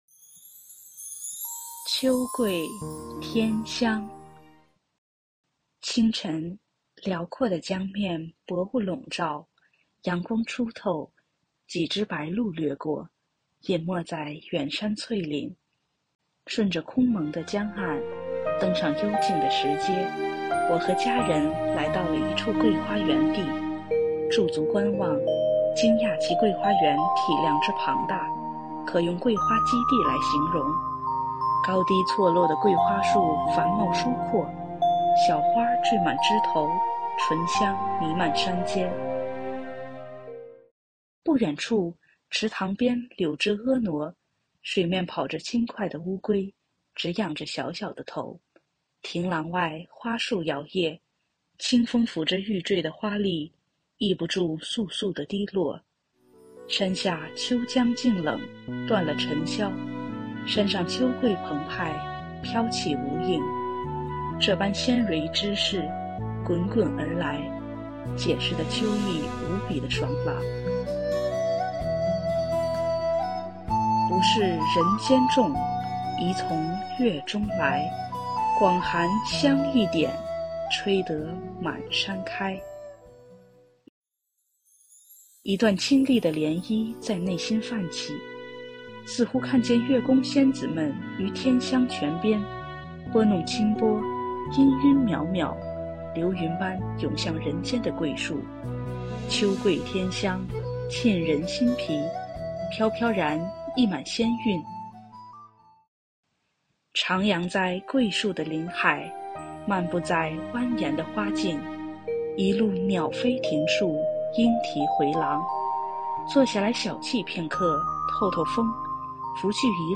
配樂朗誦（音頻）：秋桂天香